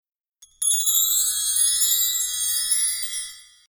ウインドチャイム（キラキラしたやつ。）
キラキラキラ～みたいなやつです。笑
これがウインドチャイムという音です。